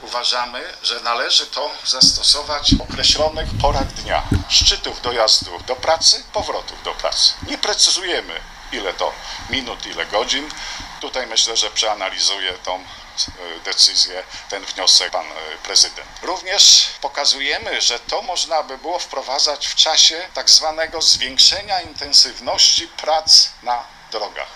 W tej sprawie odbyła się konferencja z udziałem min: Przewodniczącego Klubu Radnych ” Wspólny Koszalin ” Marka Reinholza.